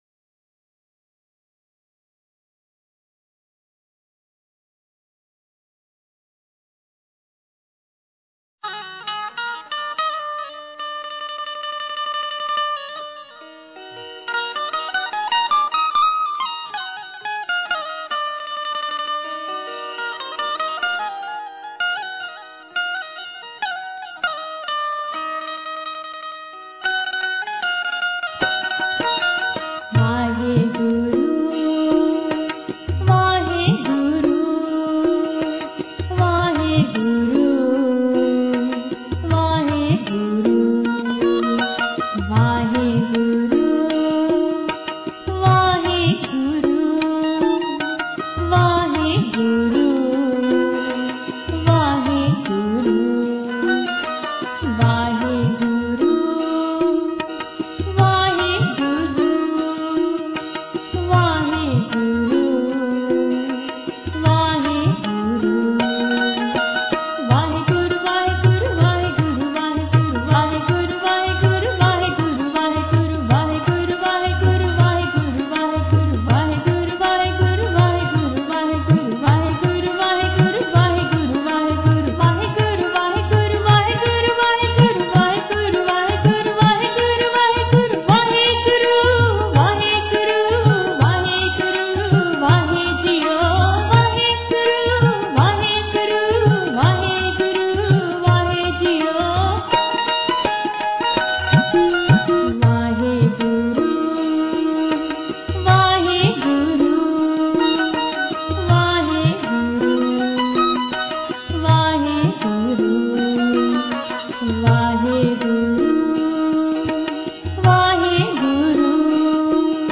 gurmantar-jaap.mp3